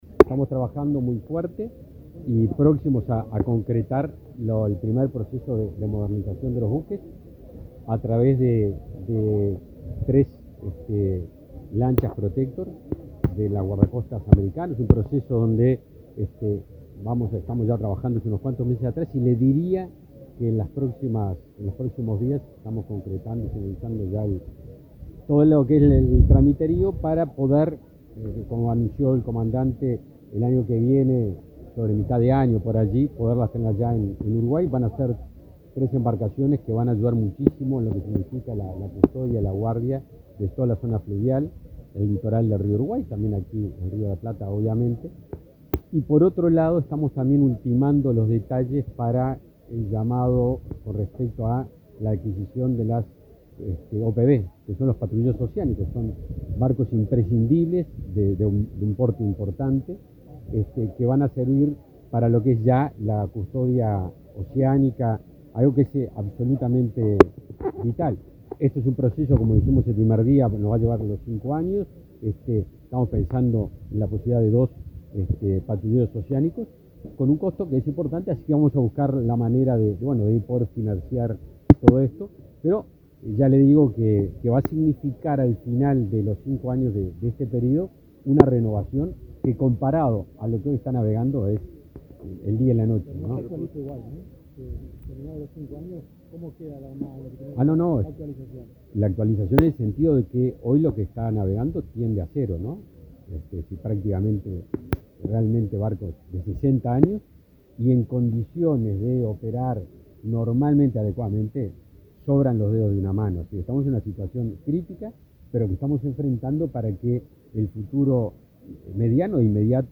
Declaraciones a la prensa del ministro de Defensa, Javier García
El ministro de Defensa, Javier García, dialogó con la prensa, luego de participar del 204.º aniversario de la Armada Nacional.